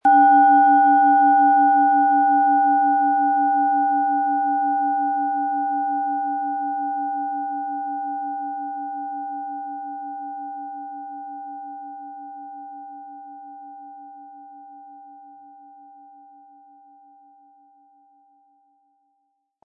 Planetenton 1
Im Sound-Player - Jetzt reinhören hören Sie den Original-Ton dieser Schale.
Viel Freude haben Sie mit einer Merkur, wenn Sie sie sanft mit dem kostenlosen Klöppel anspielen.
MaterialBronze